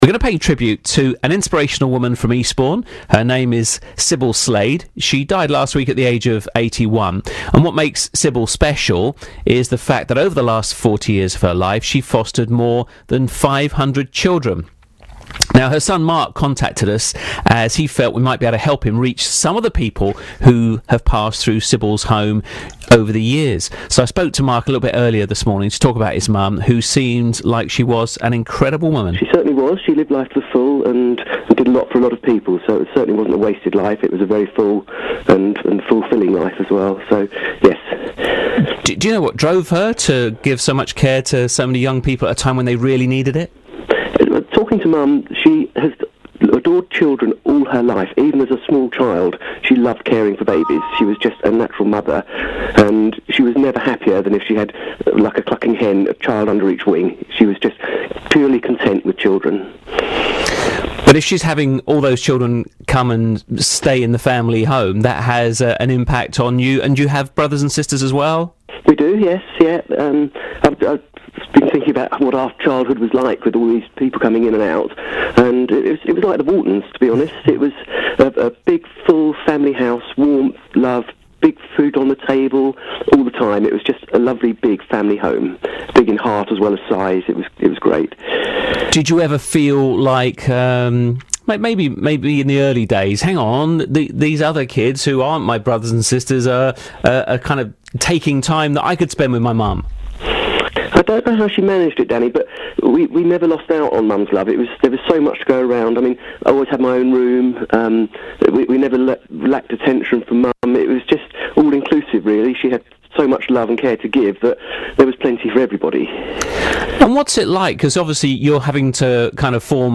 In an interview on BBC Surrey & Sussex yesterday, Surrey County Council’s Cabinet Member for Children Mary Lewis spoke about the amazing work foster carers do to transform the lives of vulnerable children.
Mary’s interview starts at 6:30.
Audio courtesy of BBC Surrey & Sussex